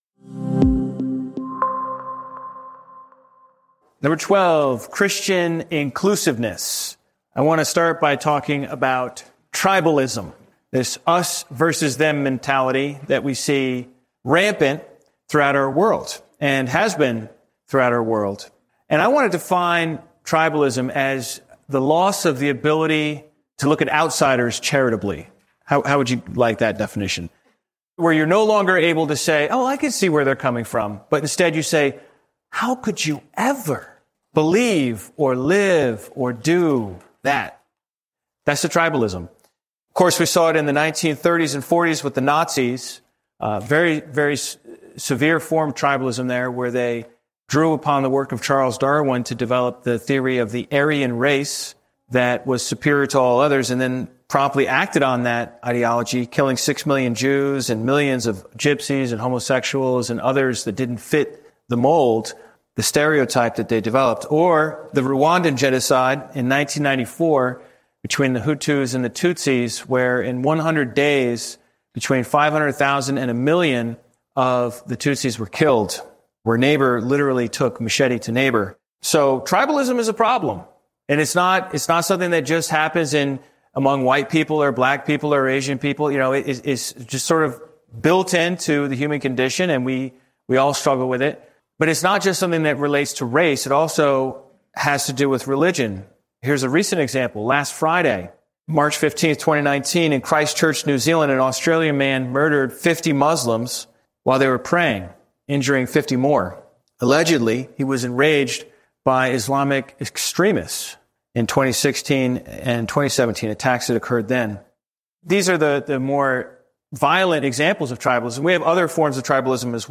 Through parables and Scripture, the teacher shows that the narrow way of Christ leads to wide embrace.